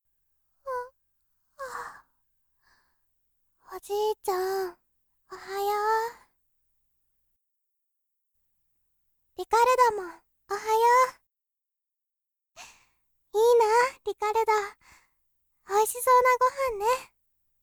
メインキャラクター・サブキャラクターの、イラスト＆簡単な紹介＆サンプルボイスです。